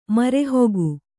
♪ mare hogu